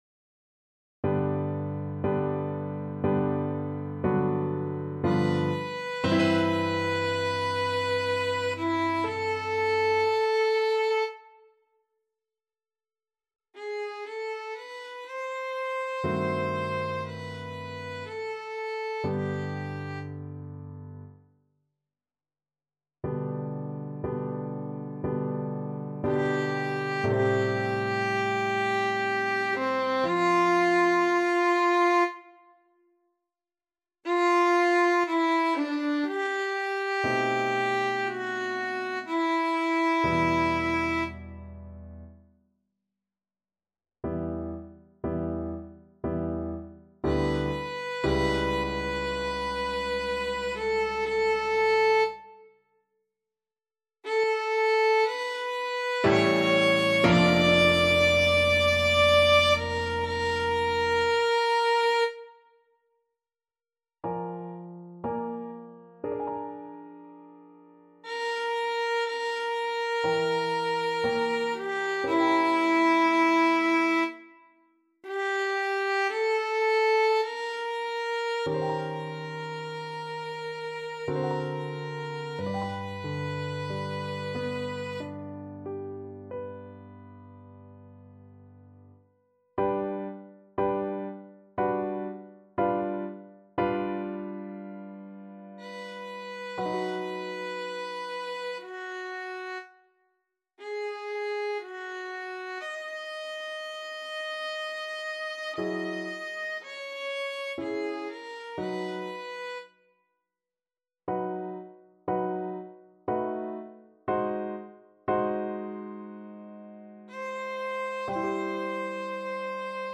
Classical Liszt, Franz Ich liebe dich, S.315 Violin version
Violin
G major (Sounding Pitch) (View more G major Music for Violin )
3/4 (View more 3/4 Music)
~ = 60 Langsam, leidenschaftlich
Classical (View more Classical Violin Music)